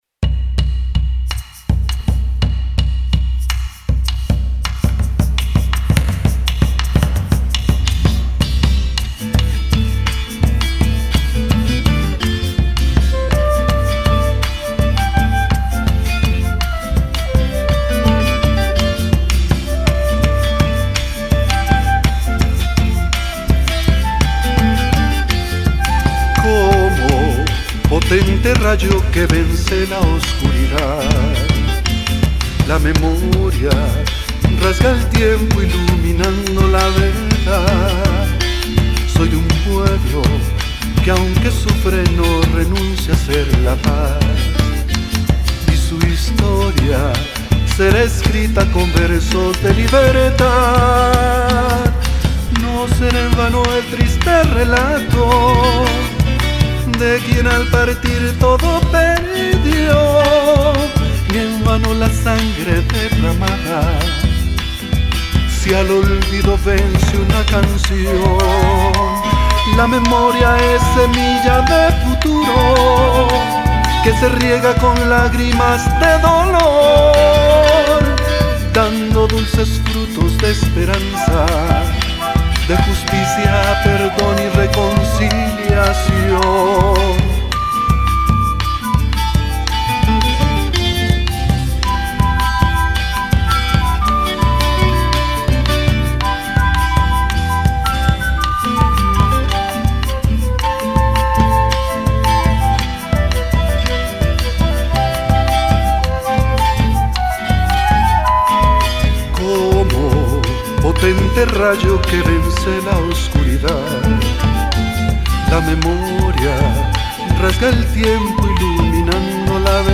Canción
dirección y voz.
tiple, bajo, percusiones.
flauta traversa.